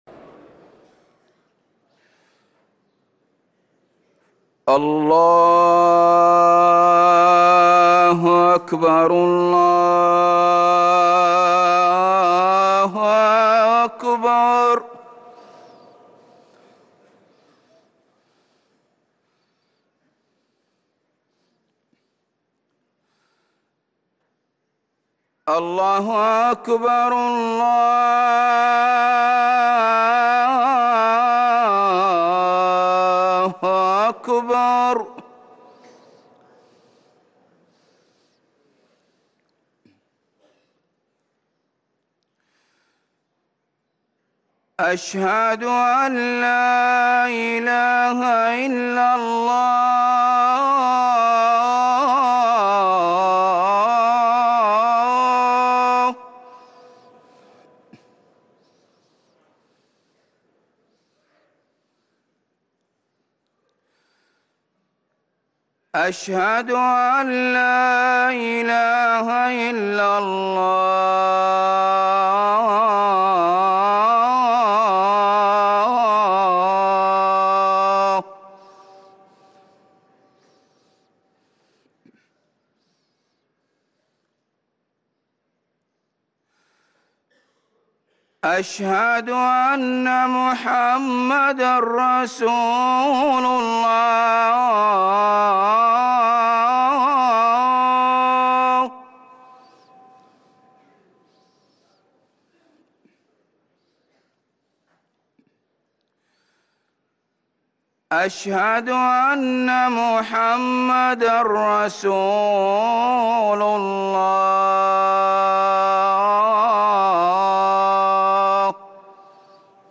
أذان الفجر للمؤذن سعود بخاري الأحد 5 ربيع الآخر 1444هـ > ١٤٤٤ 🕌 > ركن الأذان 🕌 > المزيد - تلاوات الحرمين